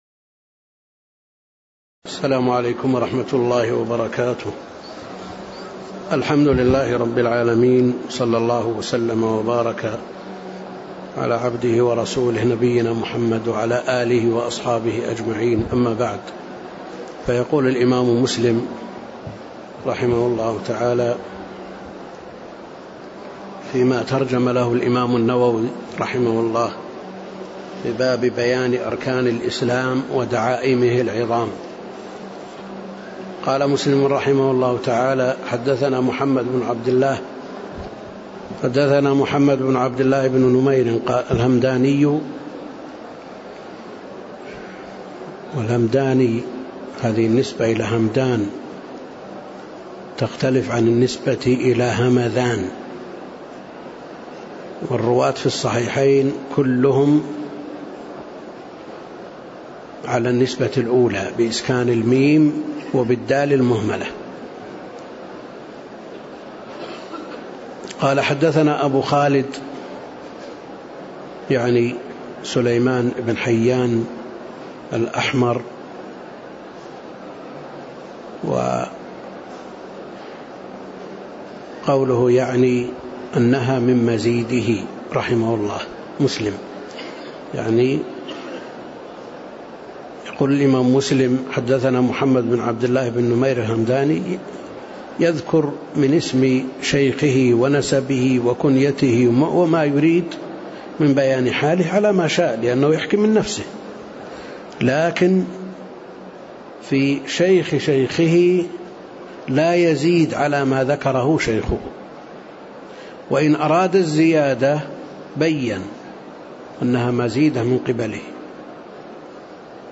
تاريخ النشر ٢٩ محرم ١٤٣٤ المكان: المسجد النبوي الشيخ: فضيلة الشيخ د. عبدالكريم الخضير فضيلة الشيخ د. عبدالكريم الخضير باب بيان أركان الإسلام ودعائمه العظام (04)